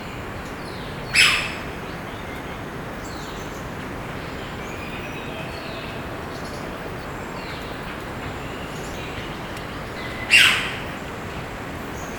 Blue Pitta
Kaeng Krachan National Park, Phetchaburi, Thailand
Blue Pitta (cyaneus) KAENG KRACHAN PE THA pew call [B] ETSJ_LS_71137.mp3